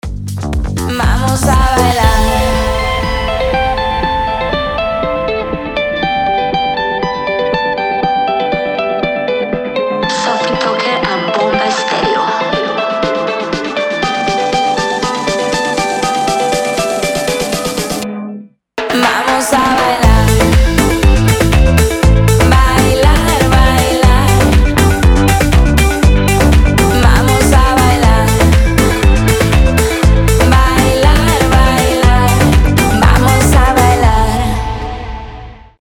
заводные
dance
Electronic
электрогитара